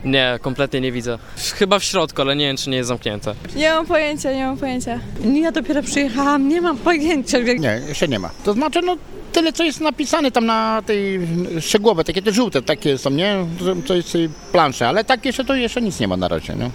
Zapytaliśmy osoby przebywające na stacji, czy widzą gdzieś informacje o odjazdach oraz czy wiedzą, gdzie mogą sprawdzić peron odjazdu.